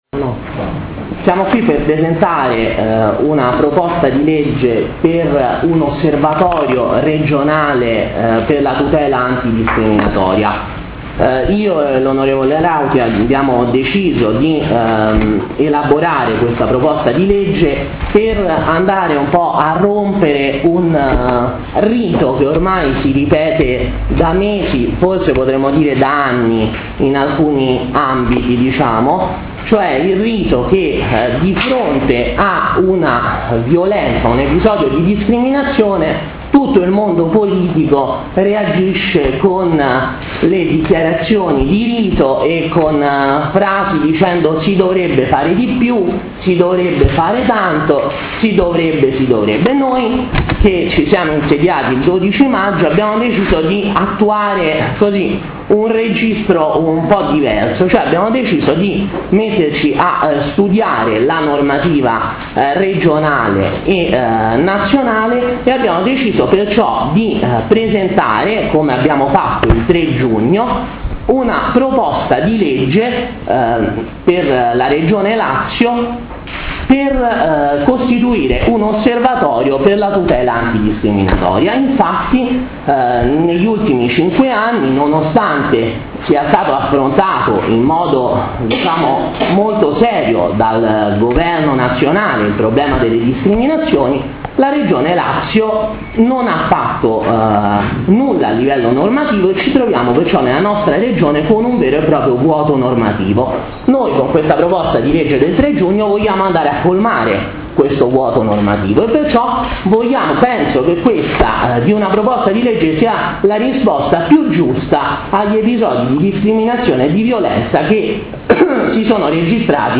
Conferenza stampa PL Osservatorio antidiscriminazione
Intervento alla conferenza stampa sulla Proposta di legge regionale per l’istituzione di un Osservatorio regionale per la tutela antidiscriminatoria.
Conferenza-stampa-PL-Osservatorio-antidiscriminazione.mp3